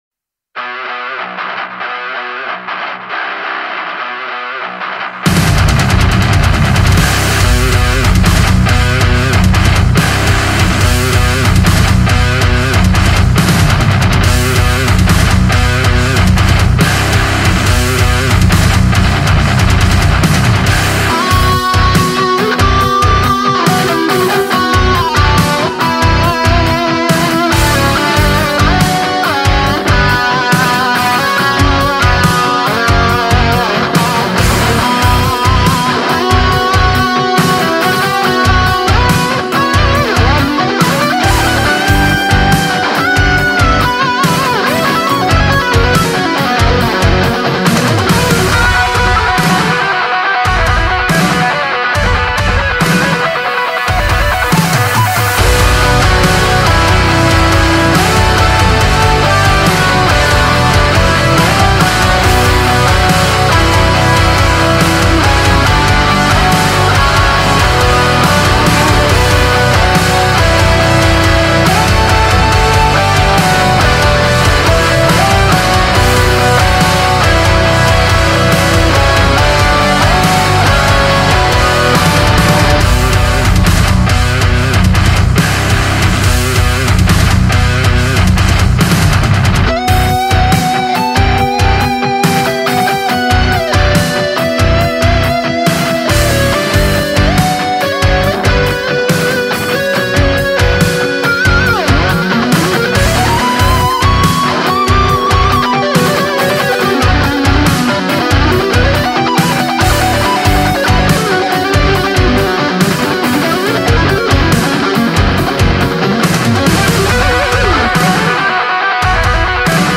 Так, ну вот что получилось у меня, проект 44100, 32 с плавающей, гейн на линии на 12, на мейн выходе так же на 12, ничего не ровнял, нормализовал и так далее, все как есть, только трек разделил на два канала при импорте для удобства Вложения Оригинал.mp3 Оригинал.mp3 9,2 MB Петля карты.mp3 Петля карты.mp3 9,2 MB